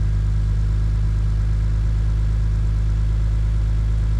rr3-assets/files/.depot/audio/Vehicles/i6_01/i6_01_idle.wav
i6_01_idle.wav